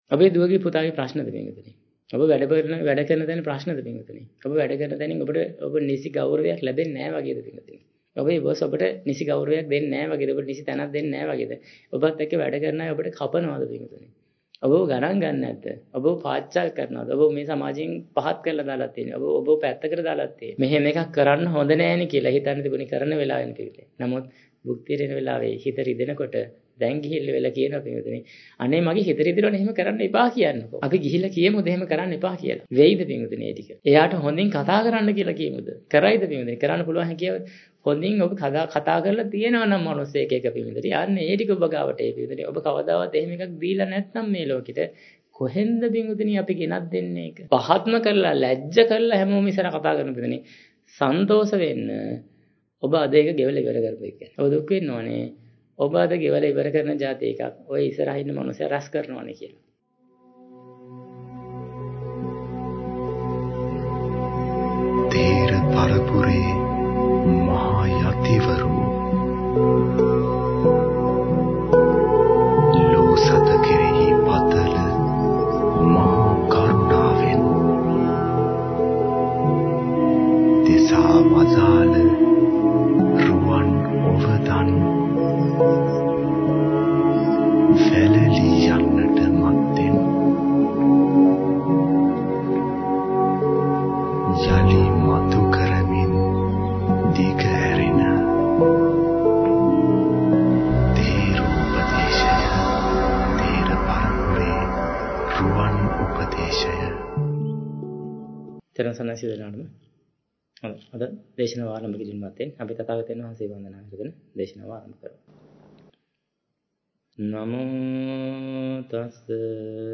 Sermon | JETHAVANARAMA